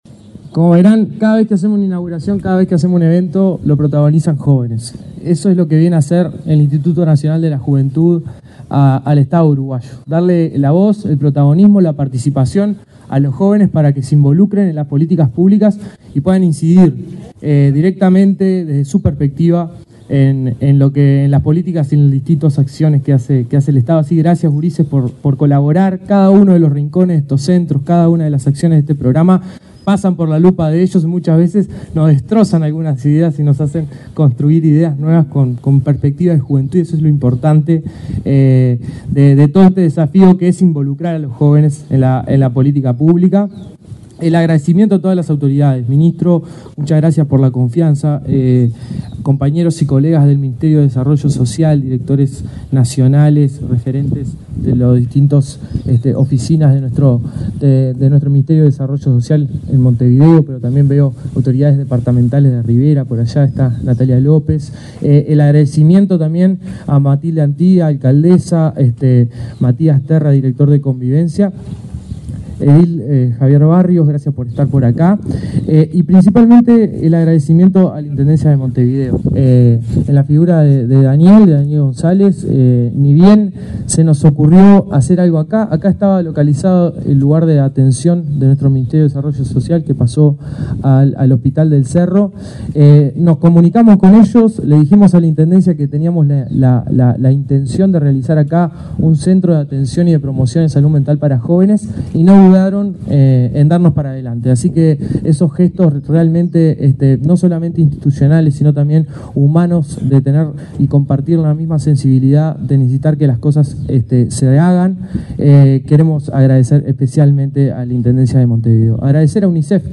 Palabra de autoridades en acto del INJU
Palabra de autoridades en acto del INJU 04/12/2024 Compartir Facebook X Copiar enlace WhatsApp LinkedIn El director del Instituto Nacional de la Juventud (INJU), Aparicio Saravia, y el ministro de Desarrollo Social, Alejandro Sciarra, participaron en la inauguración del centro Ni Silencio Ni Tabú, en el barrio Cerro, en Montevideo.